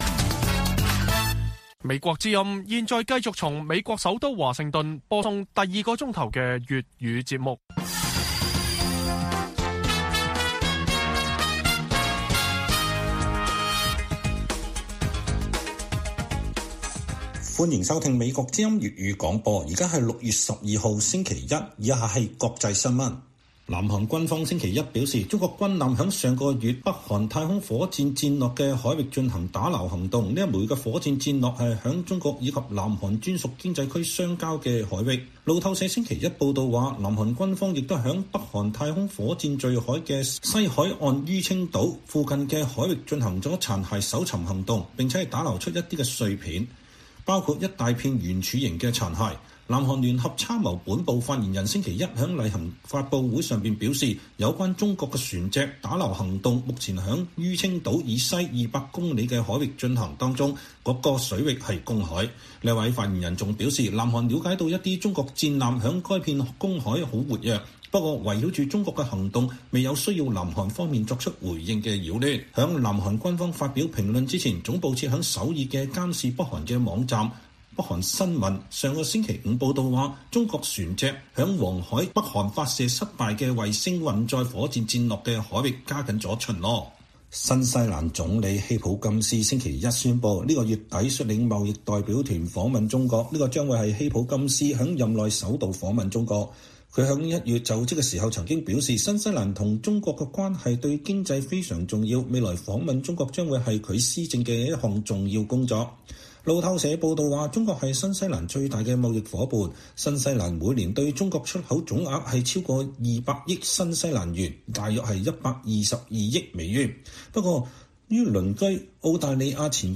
粵語新聞 晚上10-11點: 南韓指中國軍艦加緊在黃海打撈北韓發射失利濺落海中火箭殘骸